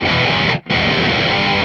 guitar02.wav